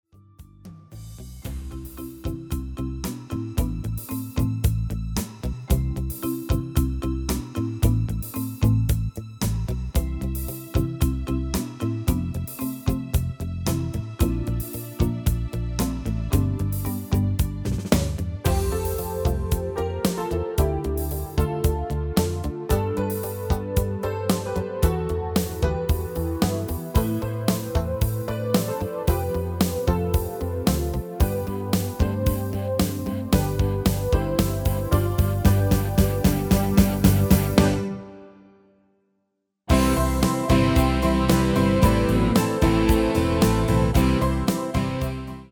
MP3-orkestband Euro 5.75